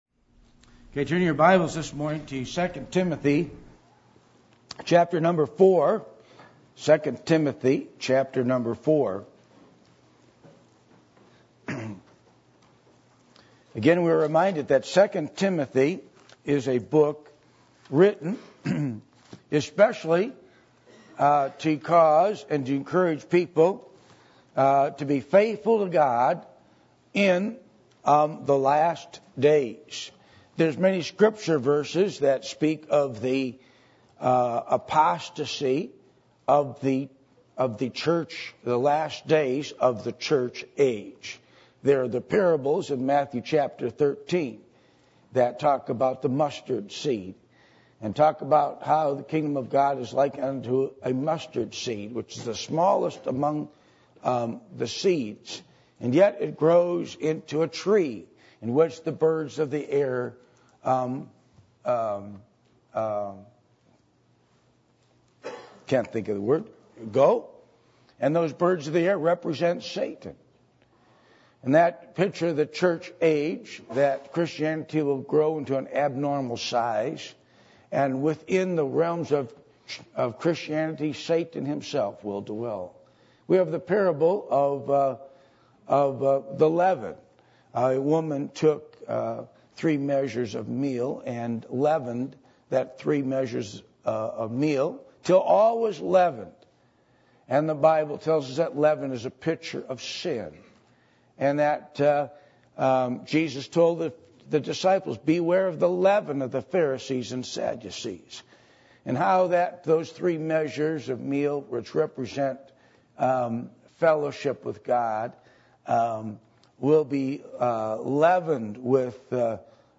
2 Timothy 4:9-18 Service Type: Sunday Morning %todo_render% « Results Of “Faith” As Found In The New Testament Wisdom